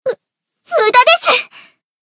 贡献 ） 分类:蔚蓝档案语音 协议:Copyright 您不可以覆盖此文件。
BA_V_Hinata_Battle_Defense_1.ogg